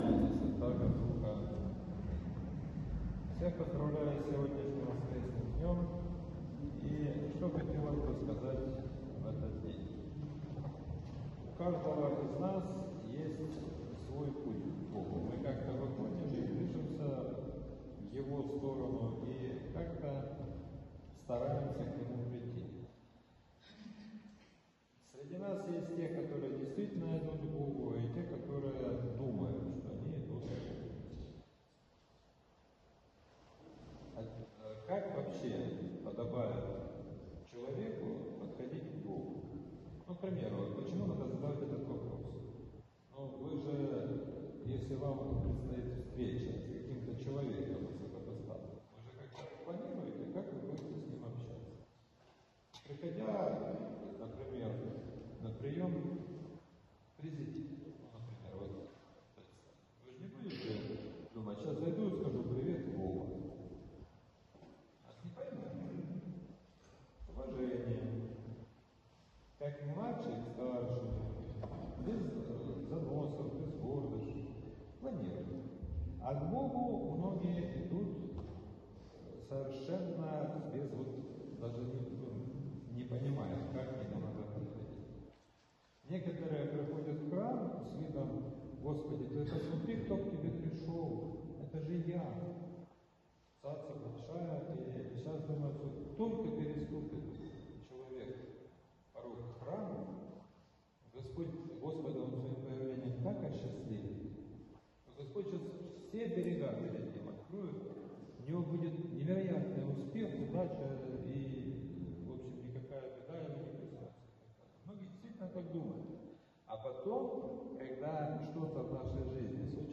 Проповедь в семнадцатую неделю по Пятидесятнице — Спасо-Преображенский мужской монастырь